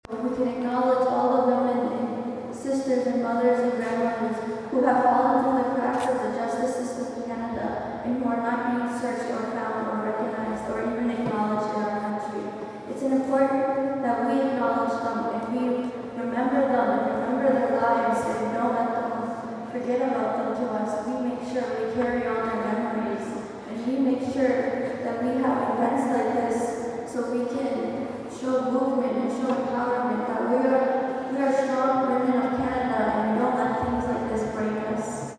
indigenous-stampede-princess.mp3